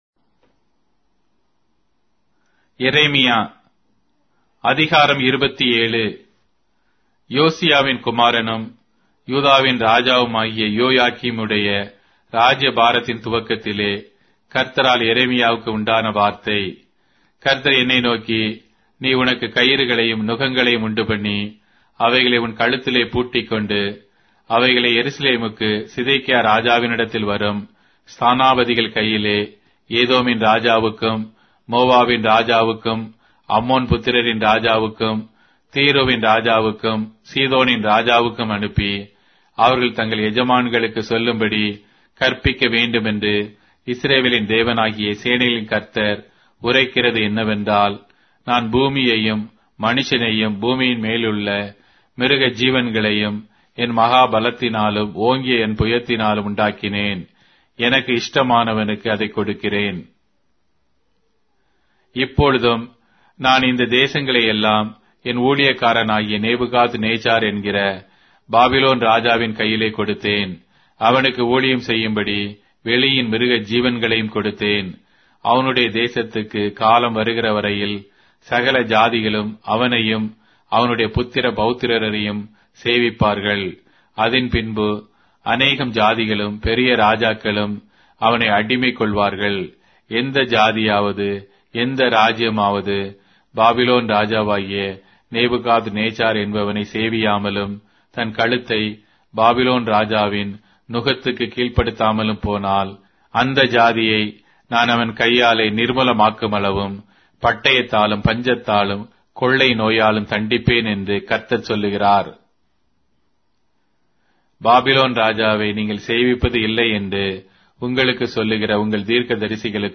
Tamil Audio Bible - Jeremiah 26 in Orv bible version